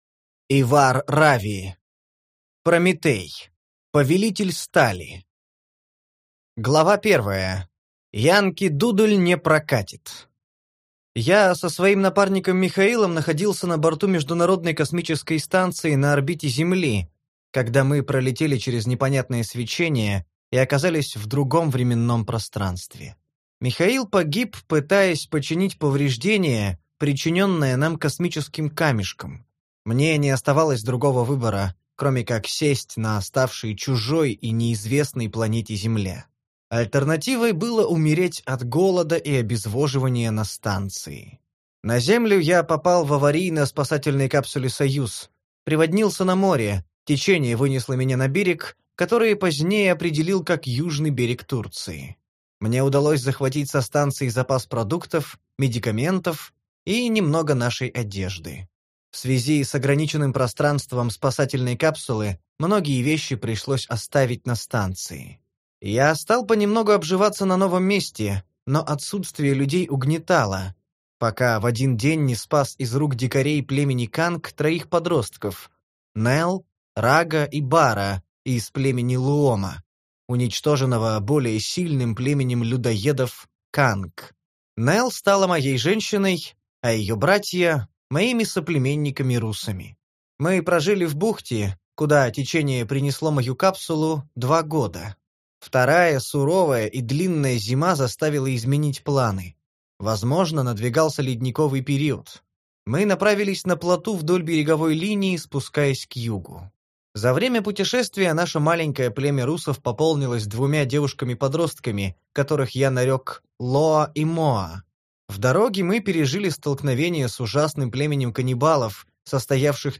Аудиокнига Прометей: Повелитель стали | Библиотека аудиокниг